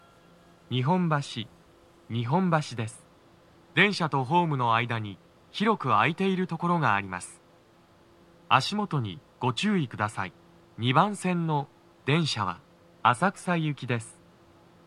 スピーカー種類 TOA天井型
足元注意喚起放送が付帯されています。
2番線 上野・浅草方面 到着放送 【男声